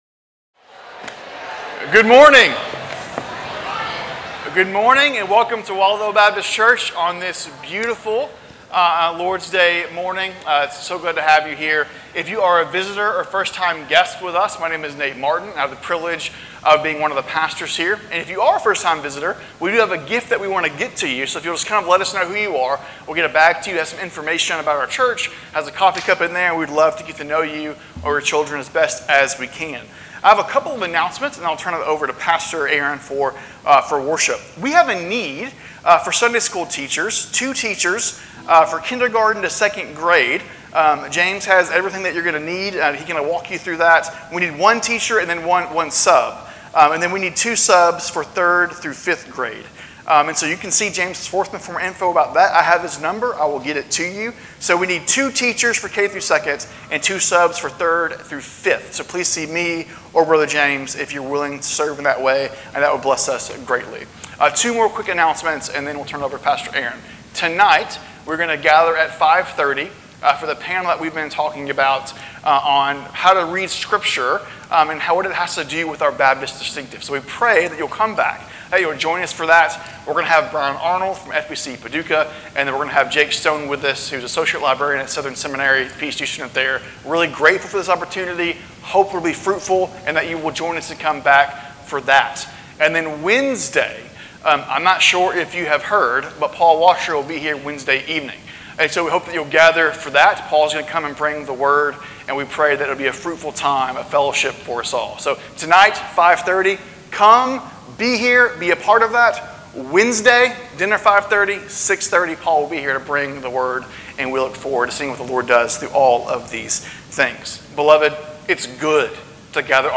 Sermons | Waldo Baptist Church